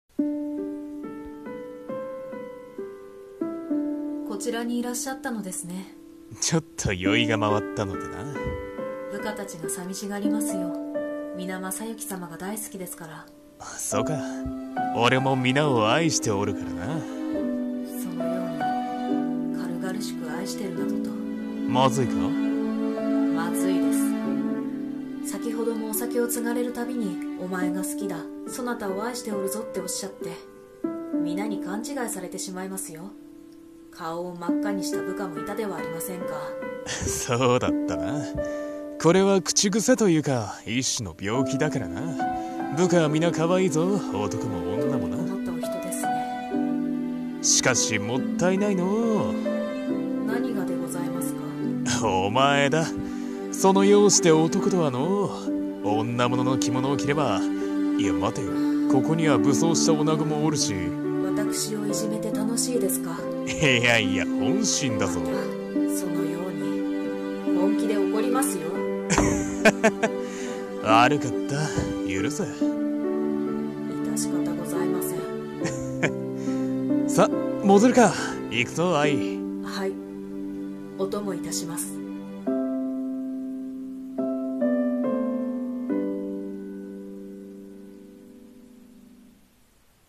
声劇 二人